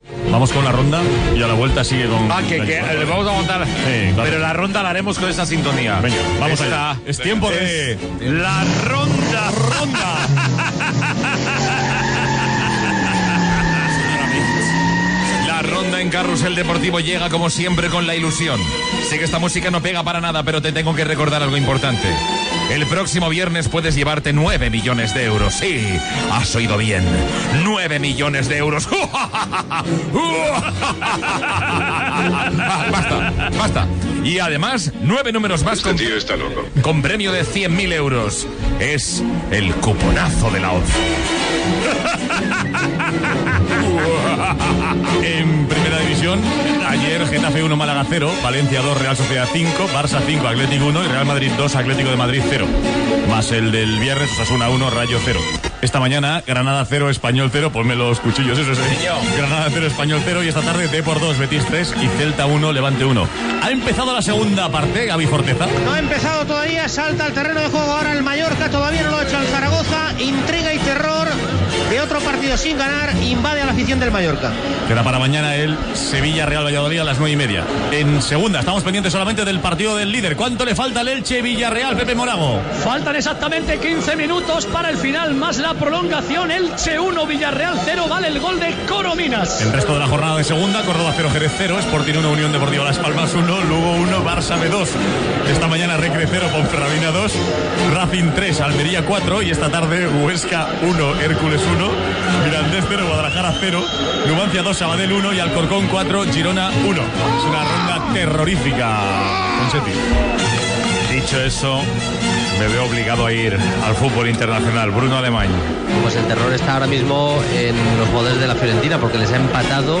Publicitat, repàs de resultats dels partits de futbol masculí acabats, connexió amb els camps de futbols de Mallorca i Elx. Futbol internacional, bàsquet i publicitat.
Esportiu